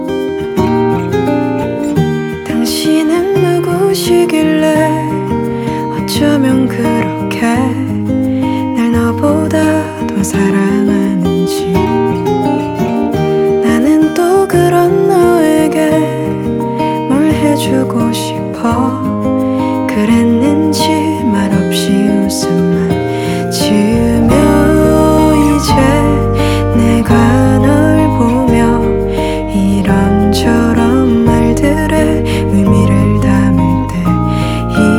Жанр: Фолк-рок